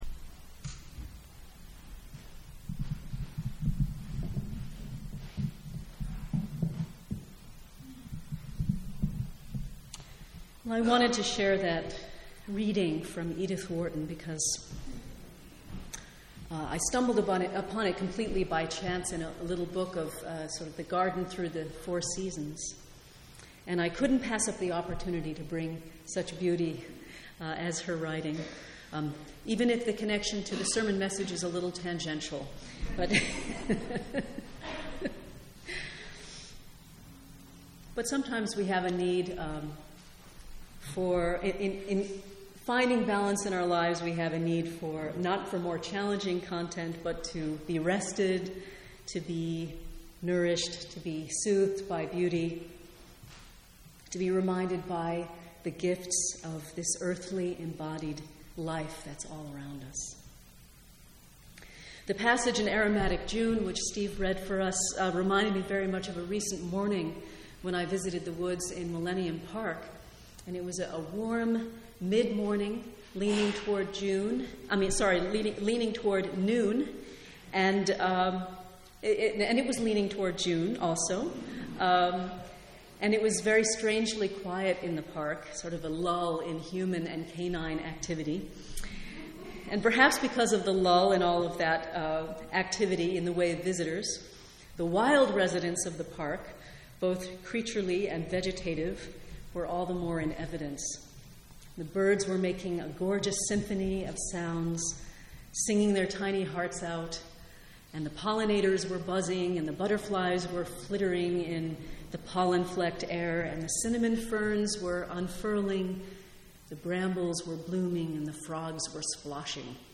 We each bring a flower (or two or three) to share for this uniquely UU version of communion, created by the Czechoslovakian Unitarian minister Nobert Capek and first introduced in Prague on June 4, 1923.